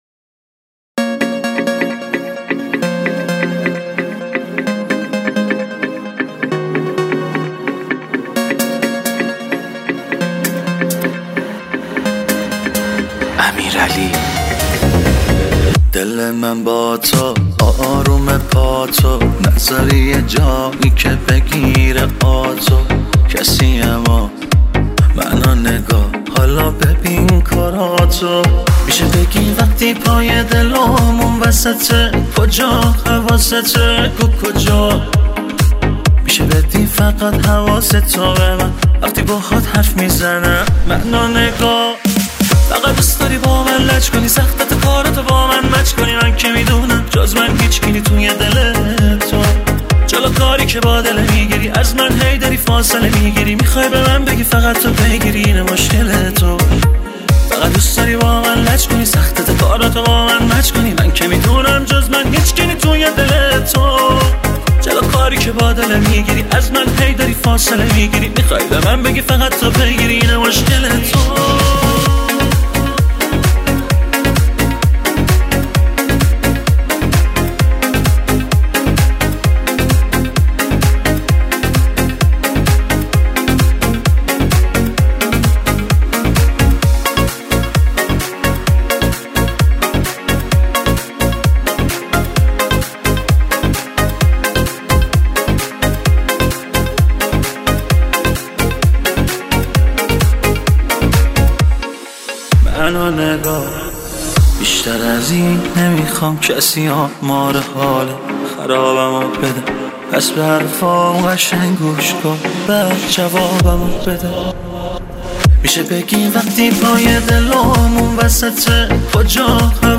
آهنگ جدید و شاد